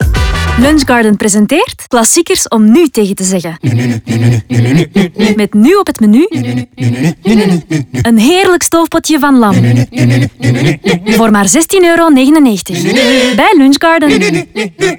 radiospotjes